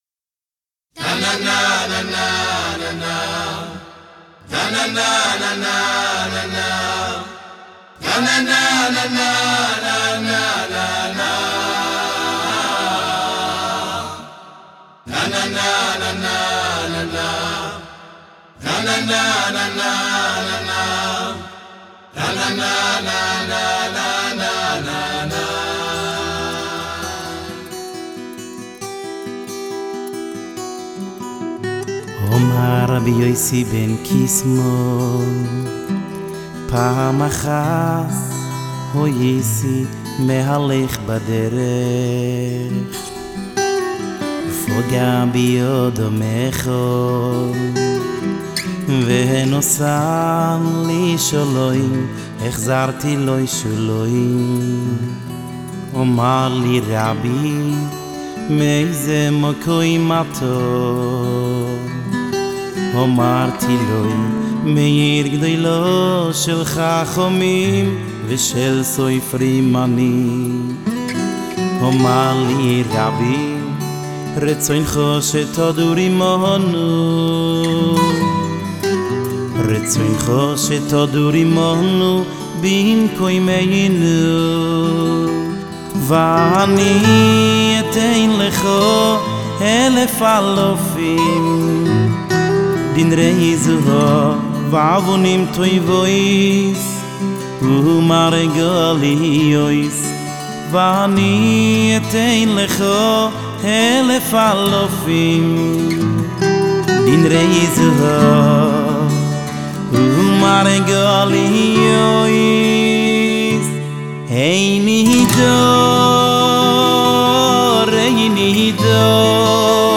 הגיטרה